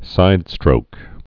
(sīdstrōk)